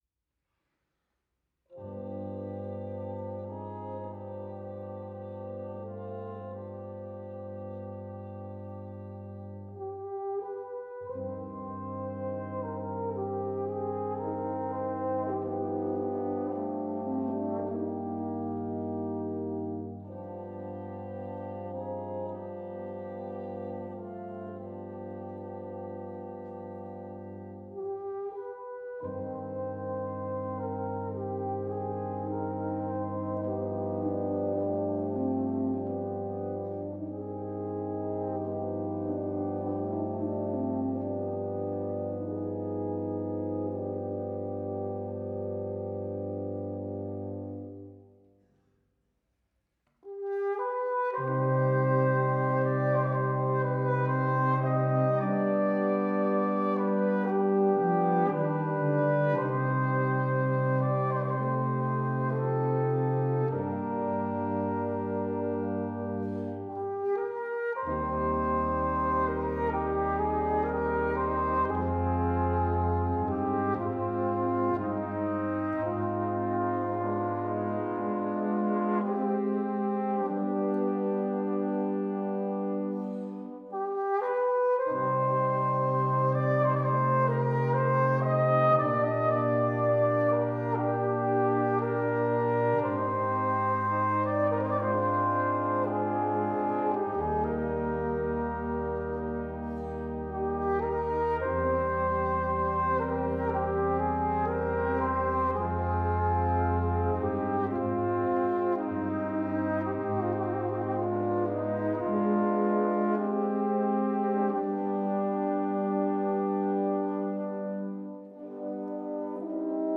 2. Brass Ensemble
10 brass players
without solo instrument
Classical
Part 4: Flugelhorn, Bb Trumpet, Bb Cornet
Part 10: Tuba – Bass clef